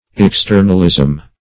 Externalism \Ex*ter"nal*ism\n.